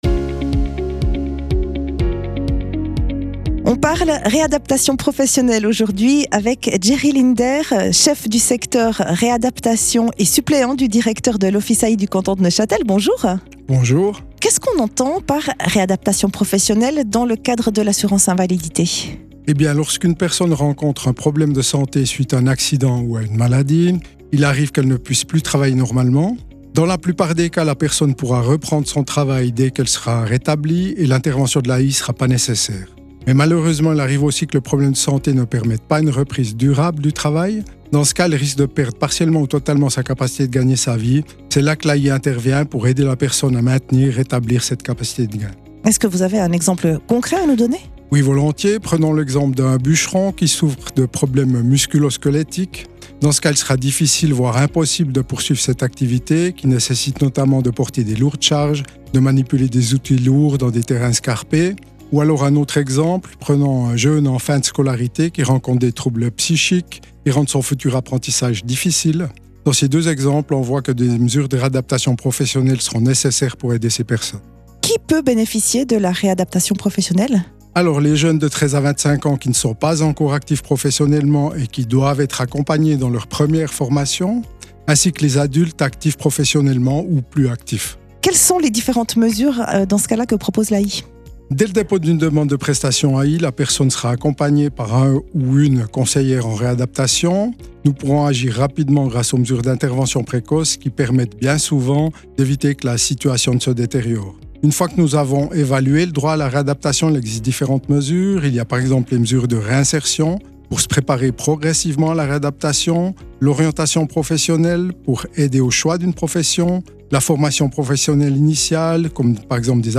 questions/réponses